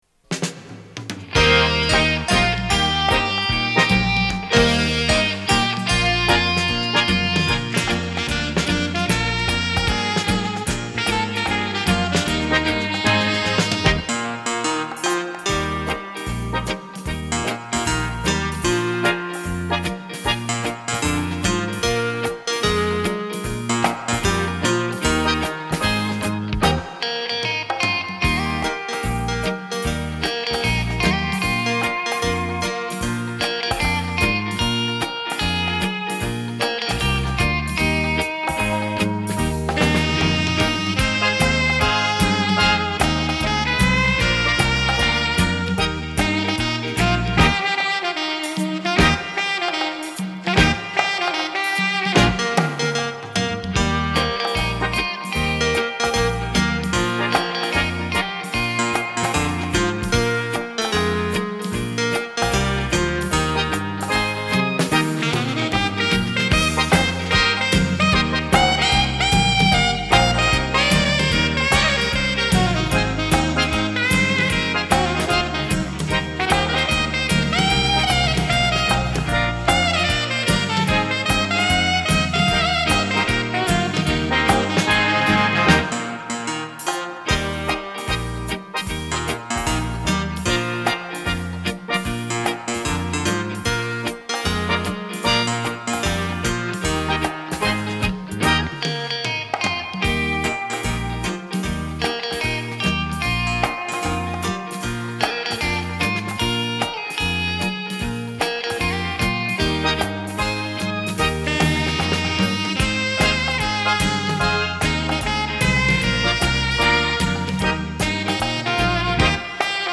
专辑音乐：社交舞曲